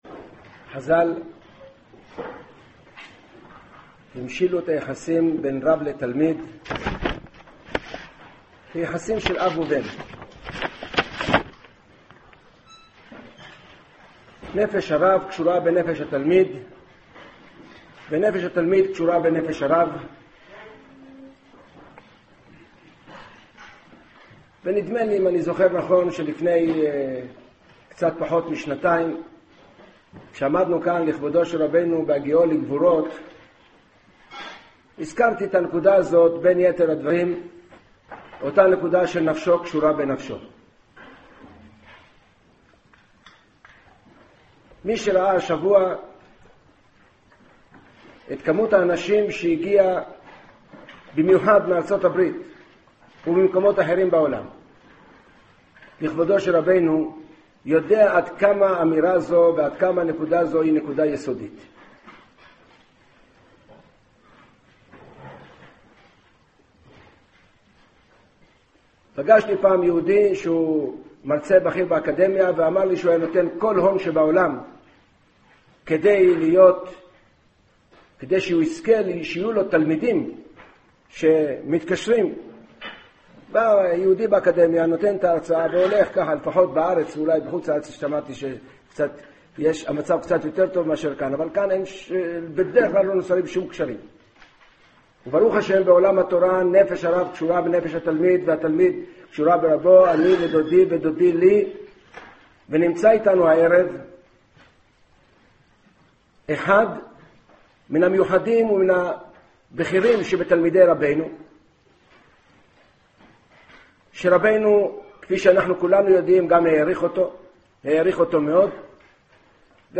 הספד לרב אהרן ליכטנשטיין
הלוייה התקיימה בב' אייר תשע"ה בבית מדרש של ישיבת הר עציון.